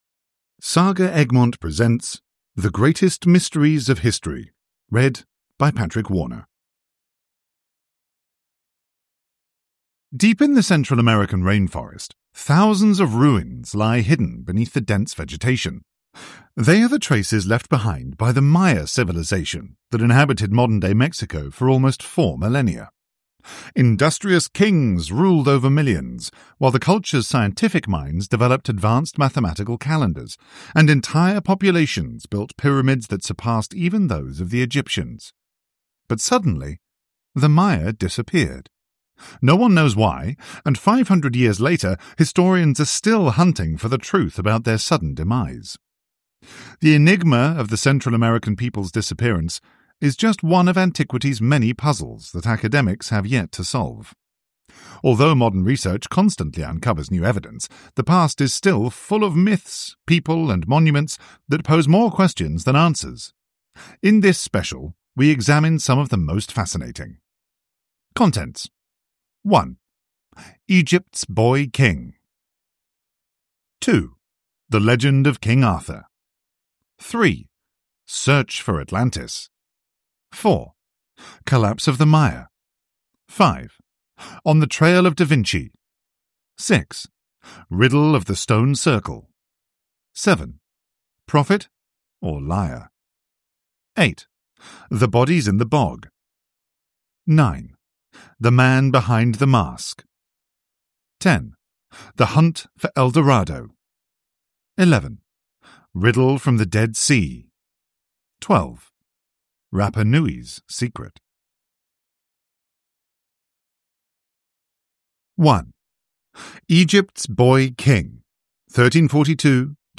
The Greatest Mysteries of History – Ljudbok